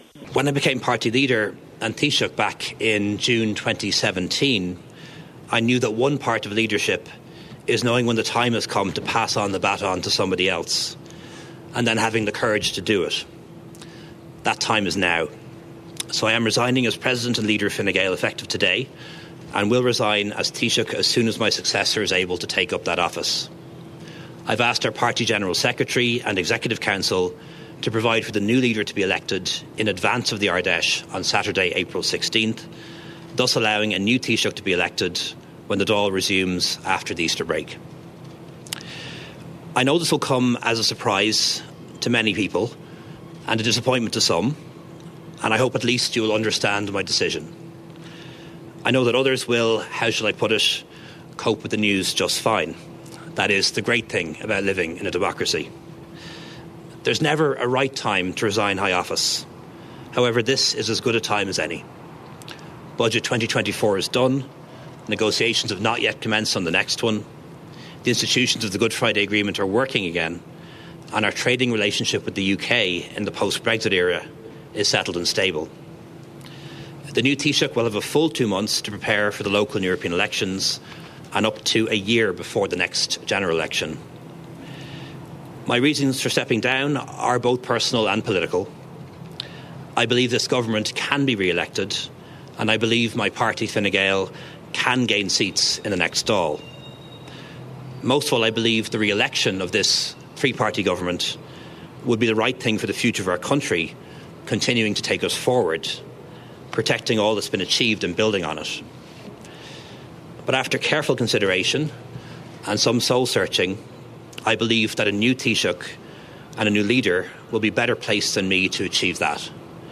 At a press conference outside government buildings, Mr Varadkar said one part of leadership is knowing when the time has come to pass on the baton to somebody else, and having the courage to do it.
lepspeech.mp3